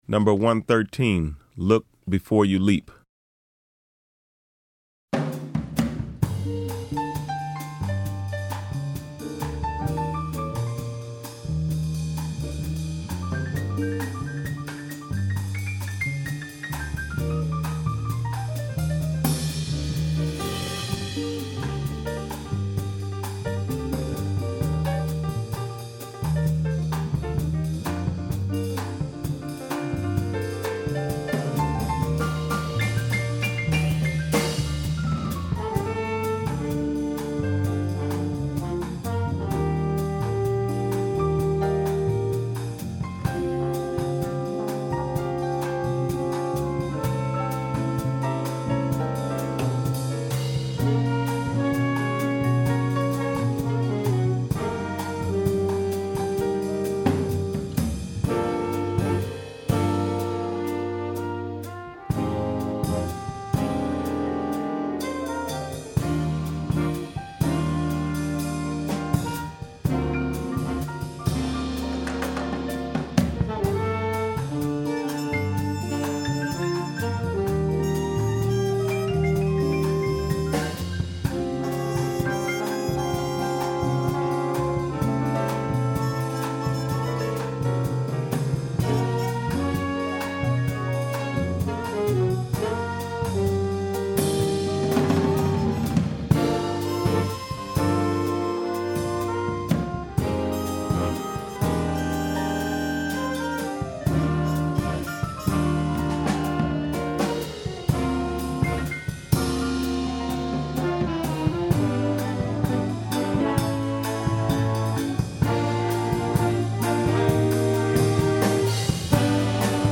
• Vibraphone
• Guitar
• Bass
• Drums